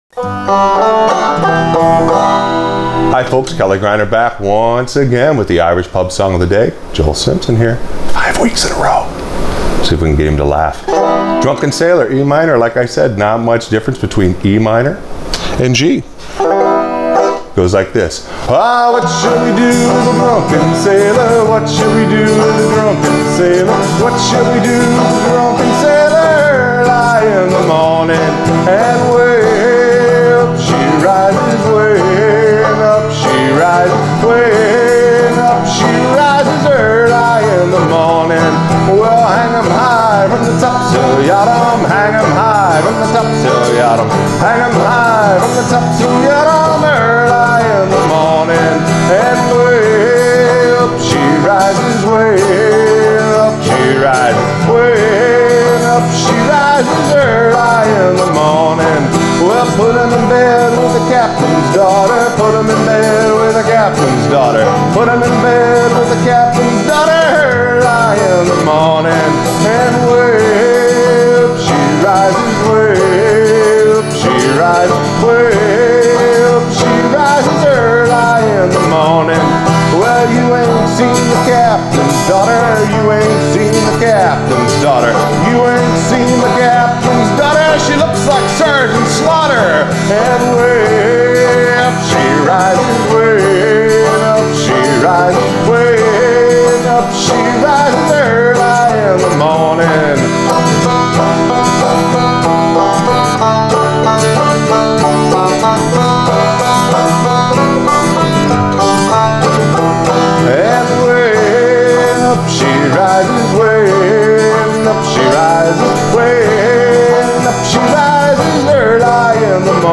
Irish Pub Song Of The Day – Drunken Sailor Accompaniment for Frailing Banjo